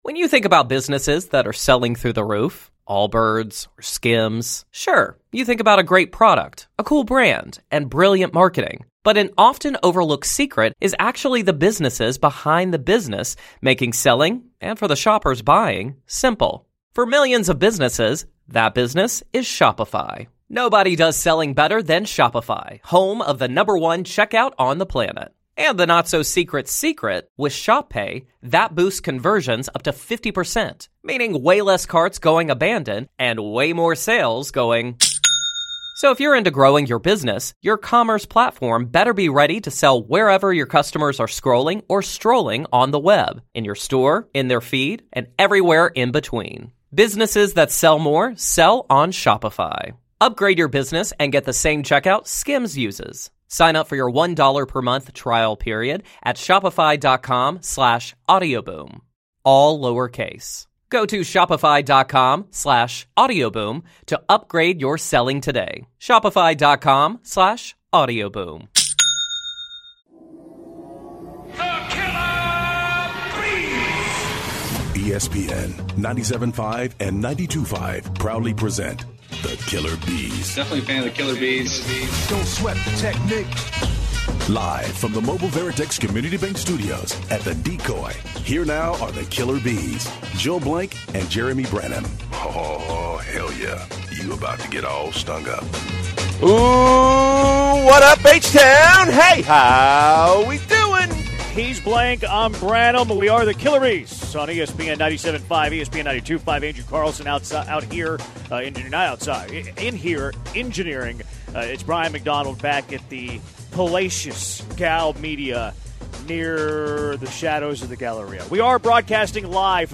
In the first hour of The Killer B's live from The Decoy: